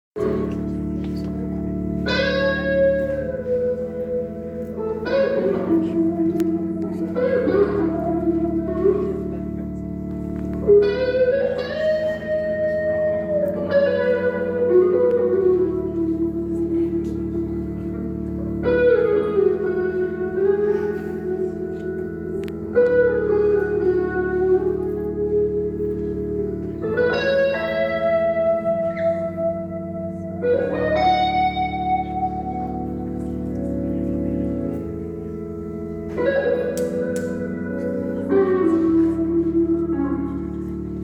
Link zum Foto- & Video-Album vom Live Konzert